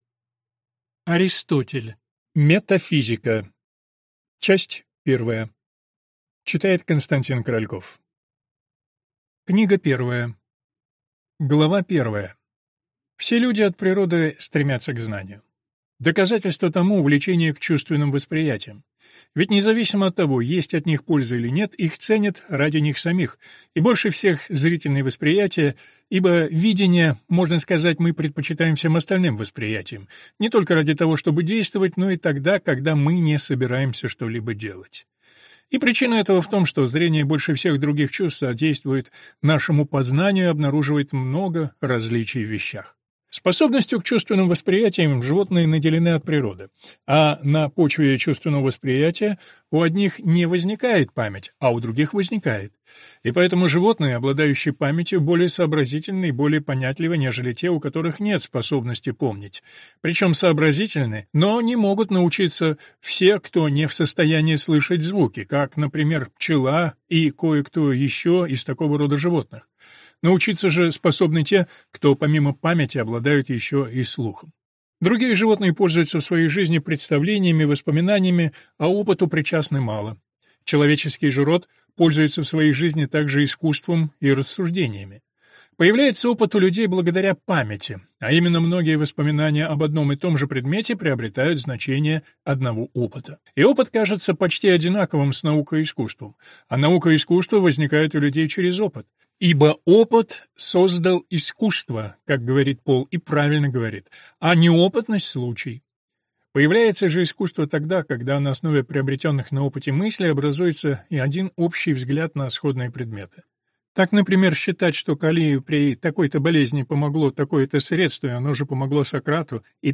Аудиокнига Метафизика. Часть 1 | Библиотека аудиокниг